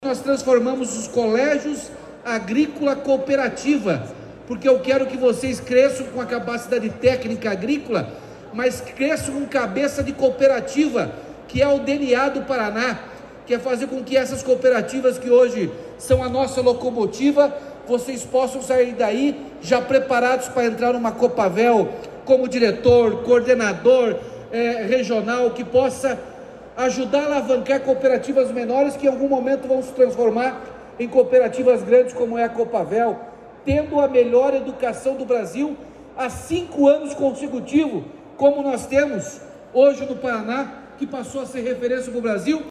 Sonora do governador Ratinho Junior sobre a modernização dos colégios agrícolas com investimento de R$ 6,8 milhões em equipamentos